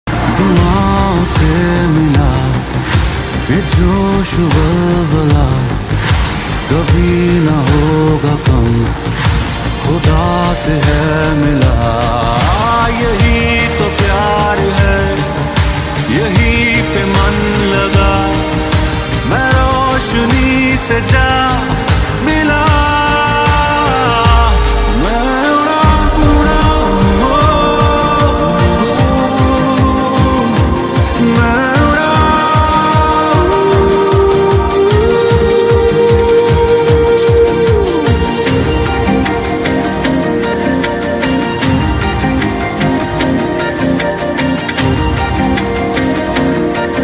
RIAT Air Show 2025 | sound effects free download
RIAT Air Show 2025 | JF 17 thunder